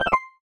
Universal UI SFX / Basic Menu Navigation
Menu_Navigation03_Close.wav